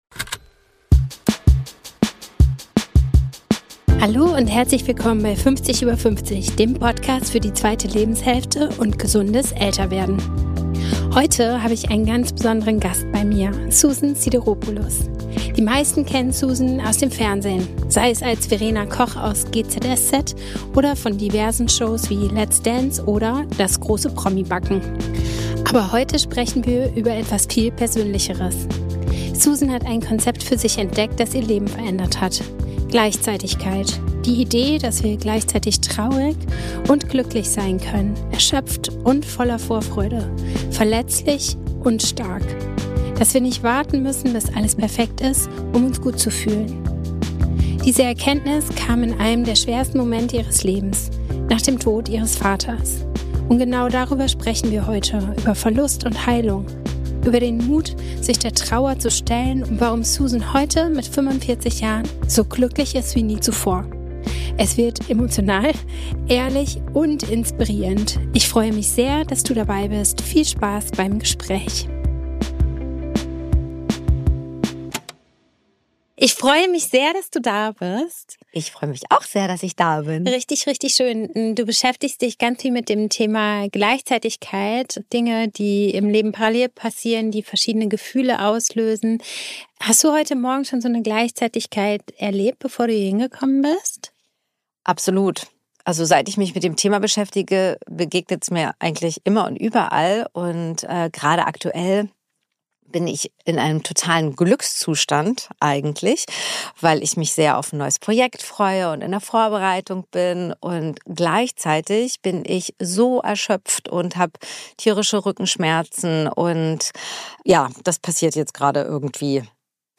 Heute habe ich einen ganz besonderen Gast bei mir: Susan Sideropoulos.
Viel Spaß beim Gespräch.